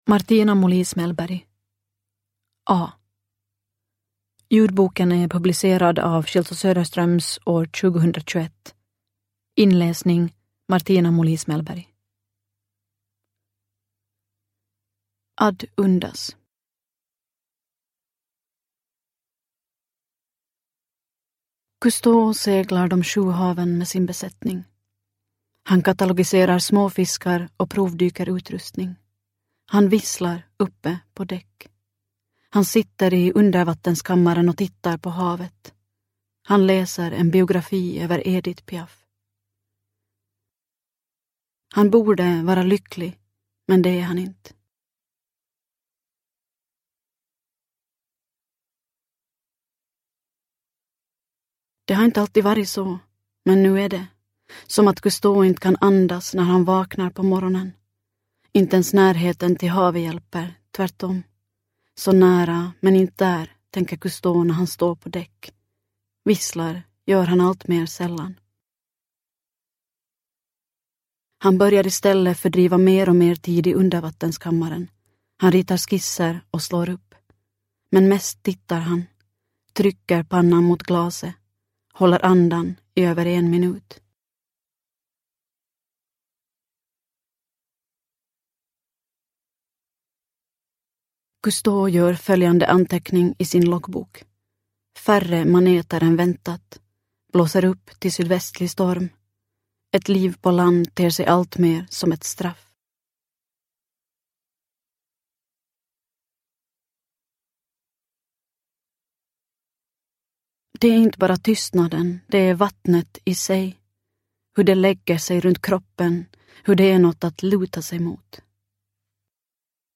A – Ljudbok – Laddas ner